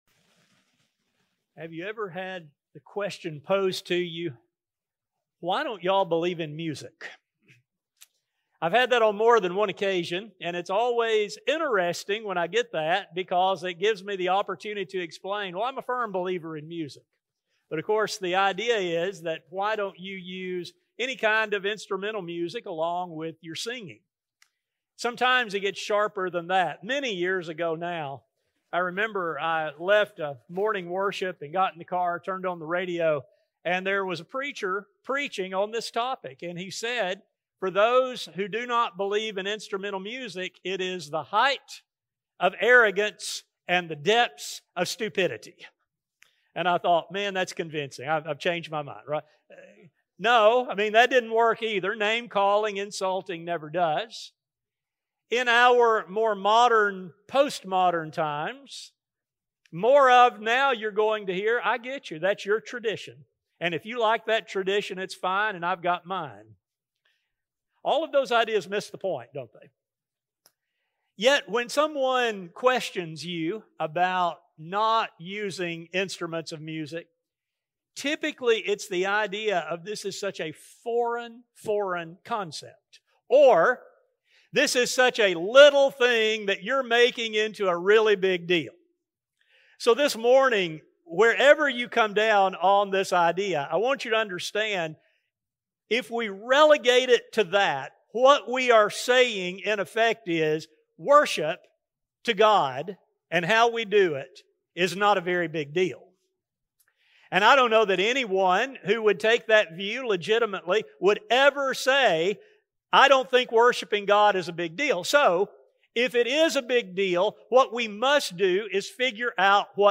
In this study, we will explore the use of instruments in the Old Testament, examine the authority for doing so, and determine whether this same line of authority instructs the people of God today. A sermon